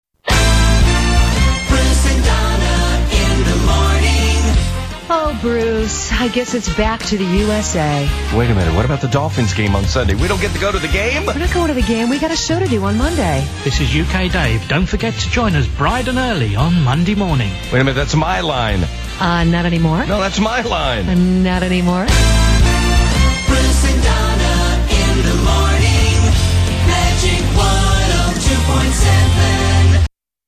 ident